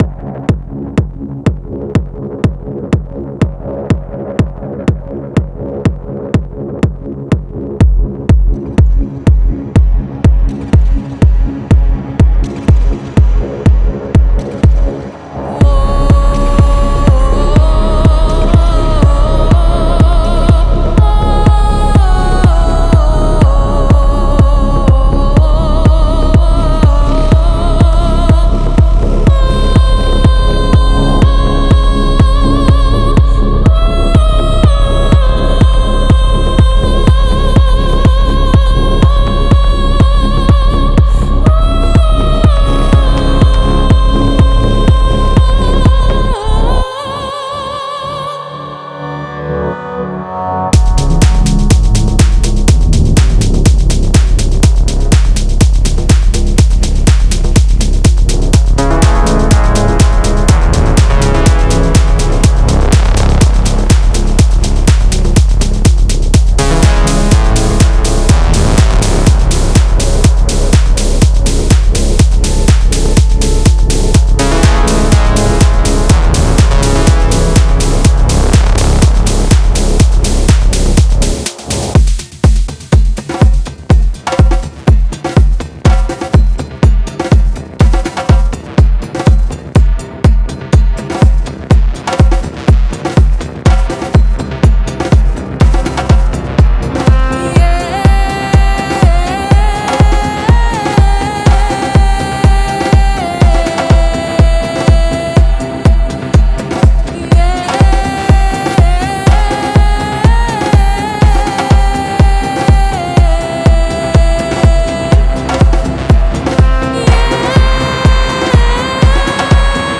メロディックテクノ系では特に使い勝手も良く、 重宝するサンプルパックです。
Genre:Melodic Techno
このサンプルパックは、100%純粋なアナログの旨味が詰まっています。
ピアノ・ループ：アナログ処理で録音された、美しく情感豊かなピアノフレーズ。
女性ボーカル・ループ：人間味と深い感情をサウンドスケープに加える、幽玄で魅惑的な女性ボーカルフレーズ。